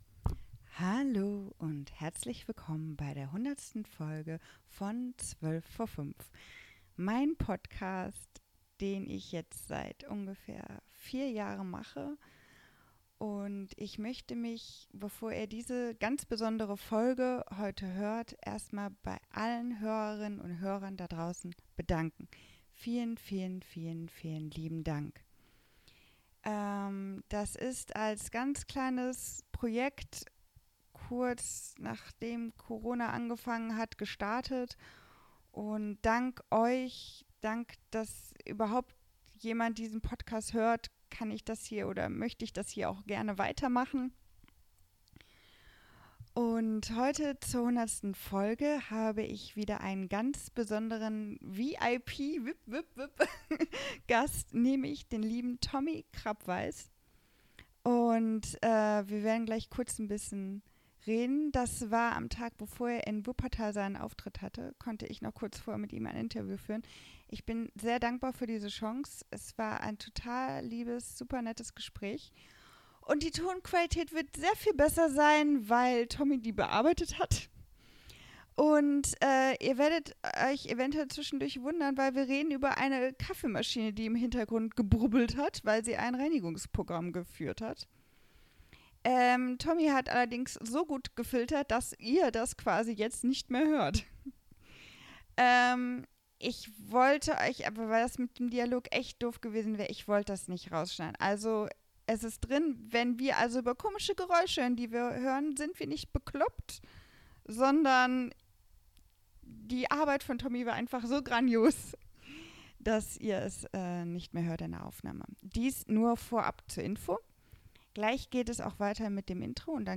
Interview mit Tommy Krappweis - Folge 100 - 12vor5 ~ 12vor5 Podcast